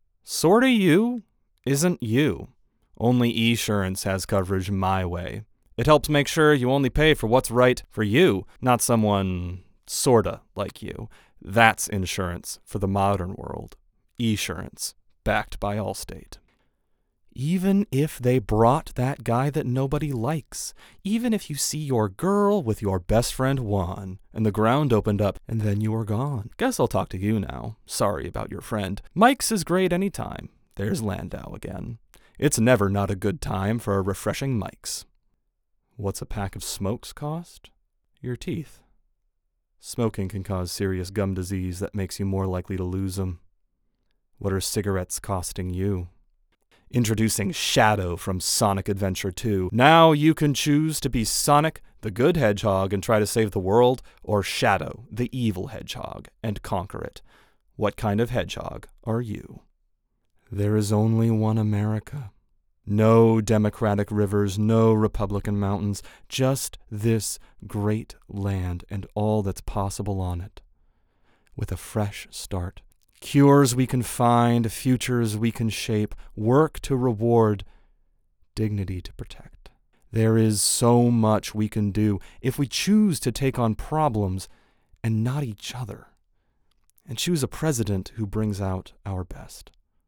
DEMO REELS FILM REEL **** MFA SHOWCASE **** Commercial Voiceover Demo
Commercial-VO-Demo-2024.wav